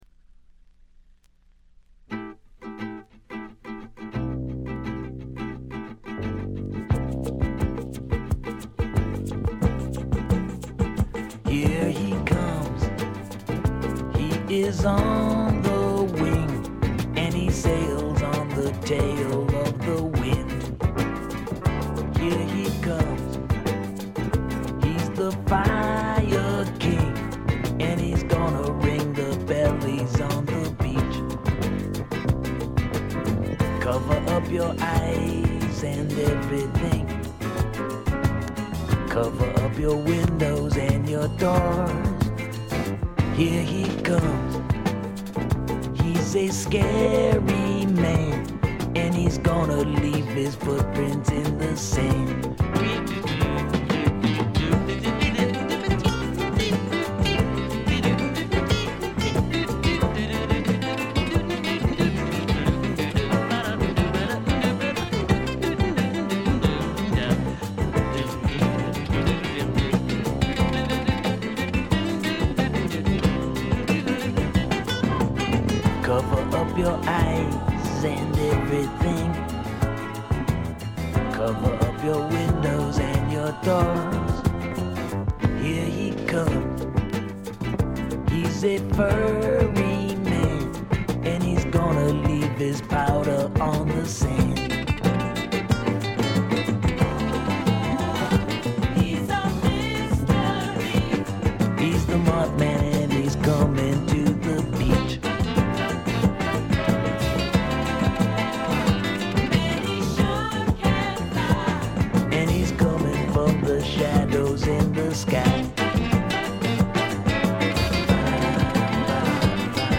部分試聴ですが、ところどころで軽微なチリプチ、散発的なプツ音少し。
試聴曲は現品からの取り込み音源です。